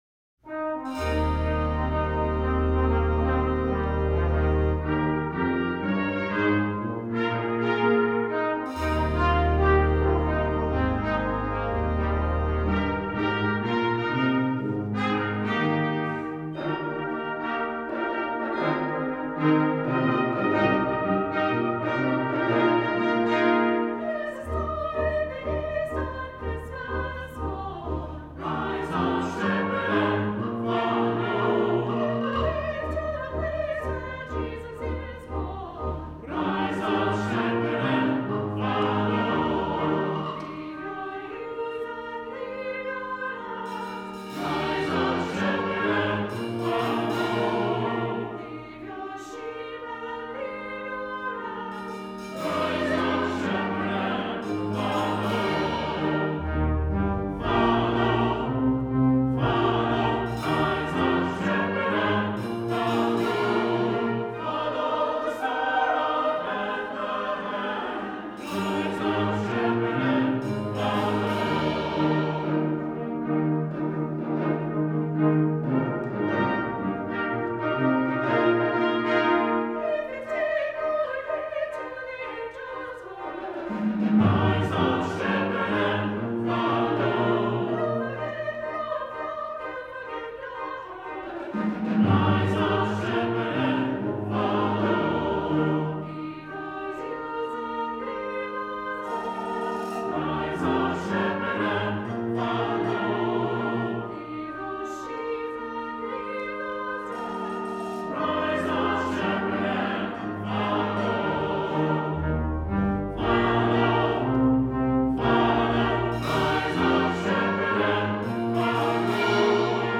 for Soprano, Brass Quintet, Percussion, and Organ (2020)